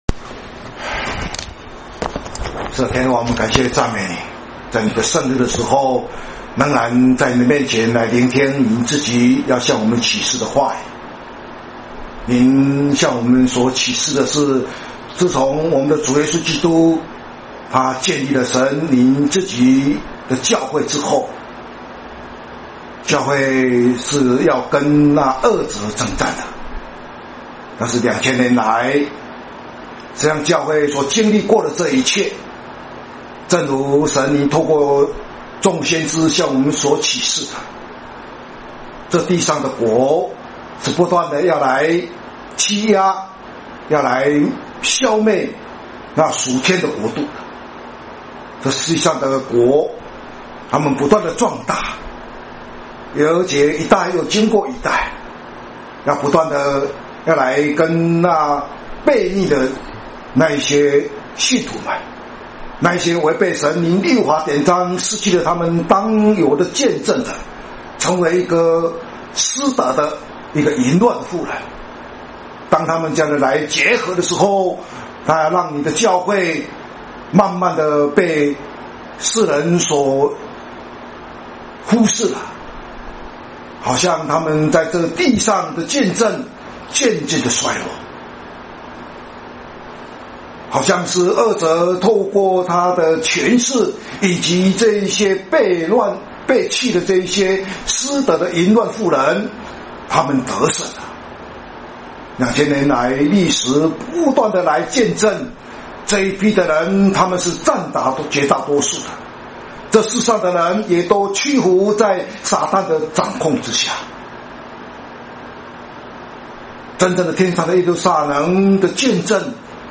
講道（啟 17:7-17:18） [雅威聖會 聖經真理研究院]